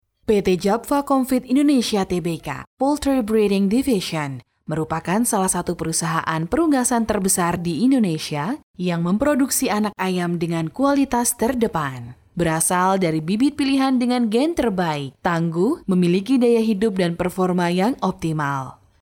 Her voice warm and natural voice and clear delivery, whether as a narrator or playing a role.
Business Corporate Casual Documentaries Educational Formal Indonesia IVR Radio MLG004_INDONESIA_CASUAL MLG004_INDONESIA_FORMAL MLG004_INDONESIA_IVR